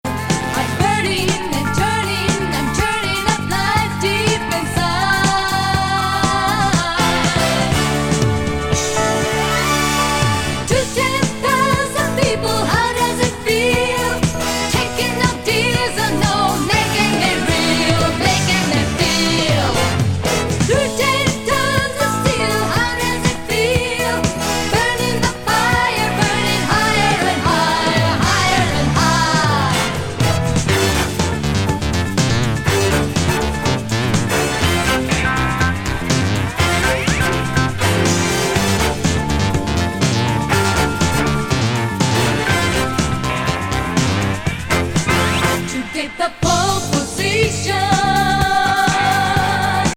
ルード挟みながらの本格派グルーヴィ・フュージョン